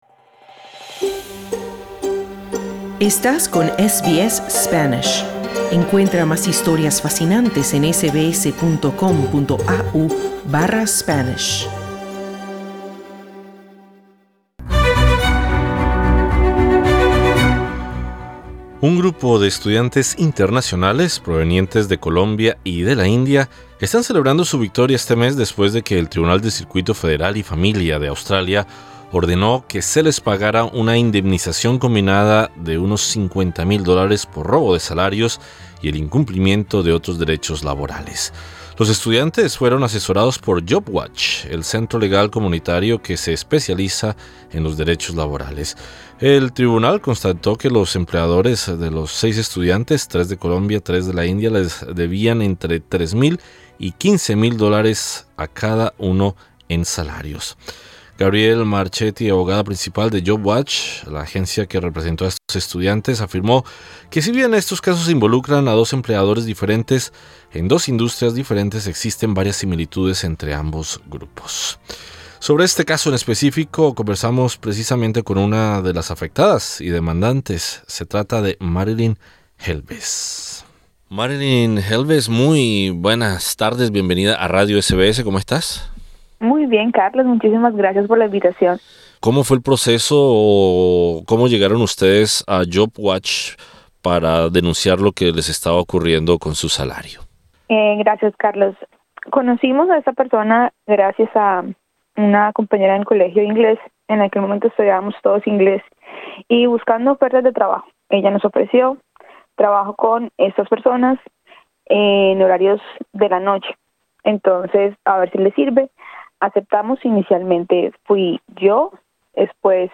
Un grupo de estudiantes internacionales provenientes de Colombia y la India, están celebrando su victoria este mes después de que el Tribunal de Circuito Federal y Familia de Australia ordenó que se les pagará una indemnización combinada de $50.000 por robo de salarios y el incumplimiento de otros derechos laborales. SBS Spanish conversó con una de las víctimas colombianas sobre su lucha por conseguir justicia.